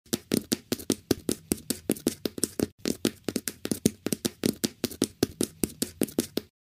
Звуки бега по бетону
Быстрый бегунок